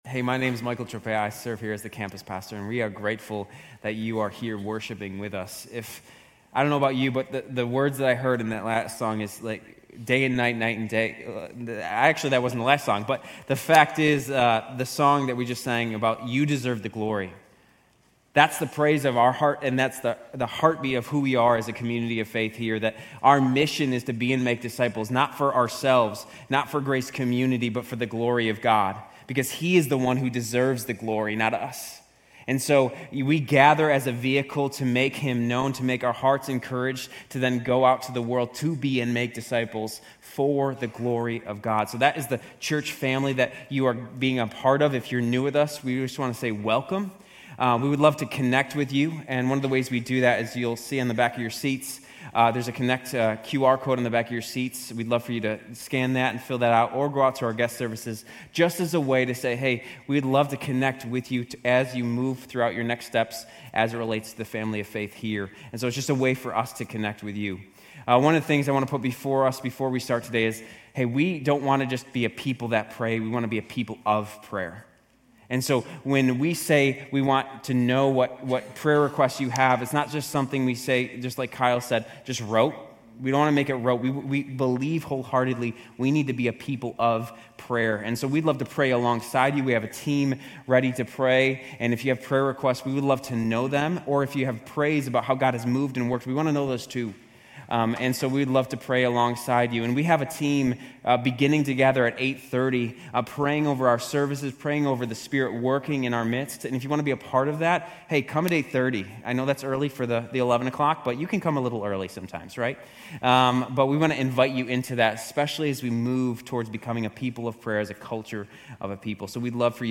Grace Community Church University Blvd Campus Sermons Gen 22 - Sacrifice of Isaac Oct 28 2024 | 00:36:16 1x 00:00 / 00:36:16 Subscribe Share RSS Feed Share Link Embed